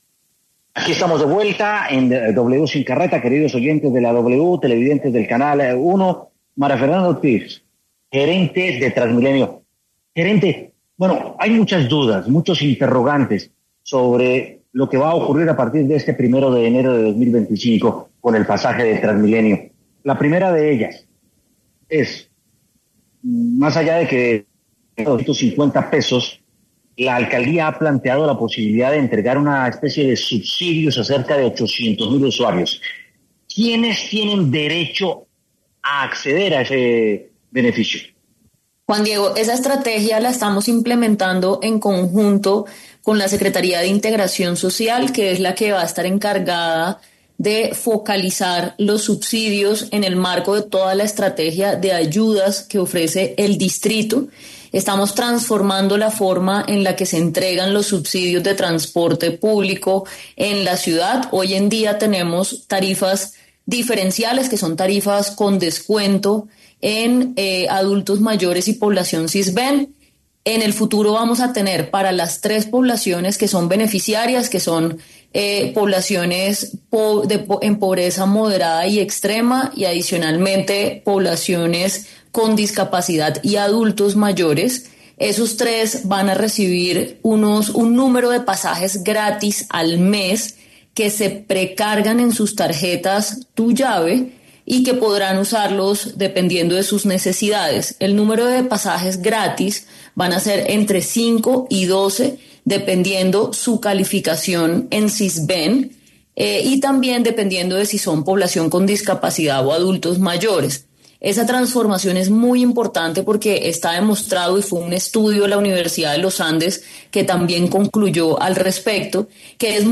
María Fernanda Ortiz, gerente de TransMilenio, habló en W Sin Carreta sobre las nuevas estrategias que se implementarán en el sistema para 2025.